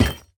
Minecraft Version Minecraft Version latest Latest Release | Latest Snapshot latest / assets / minecraft / sounds / block / vault / place4.ogg Compare With Compare With Latest Release | Latest Snapshot